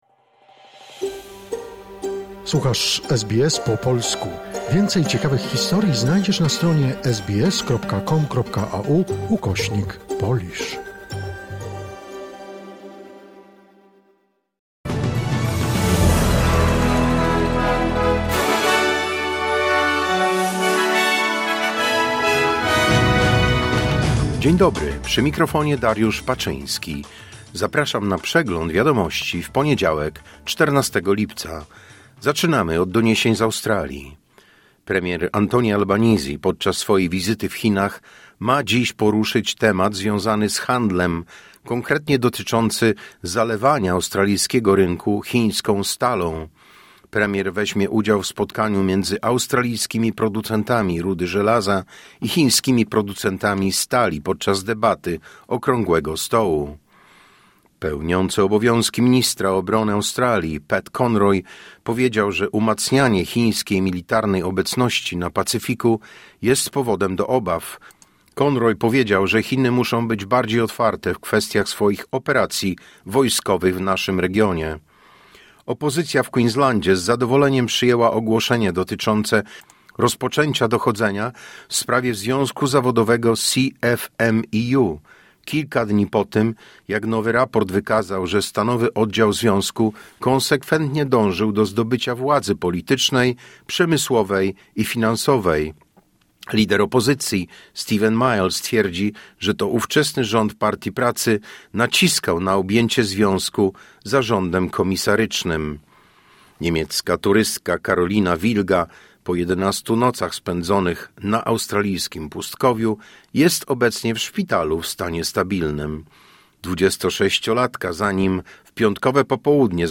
Wiadomości 14 lipca SBS News Flash